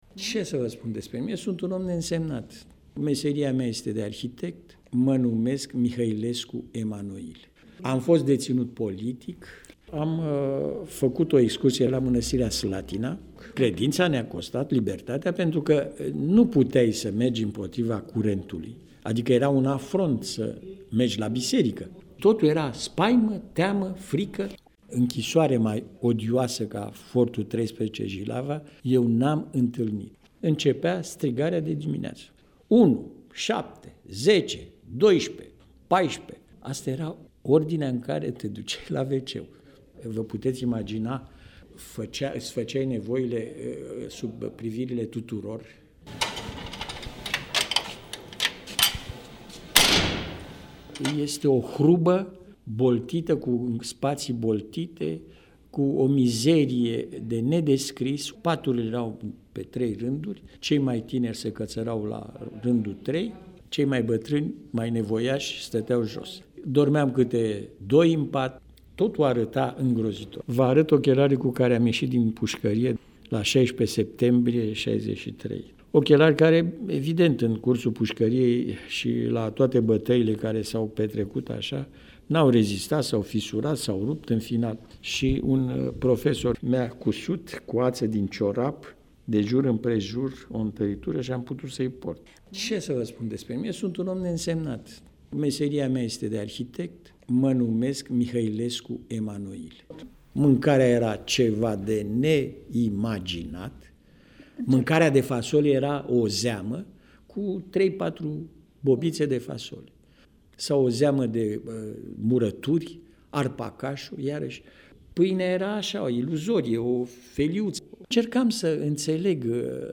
Voce-fost-detinut-politic.mp3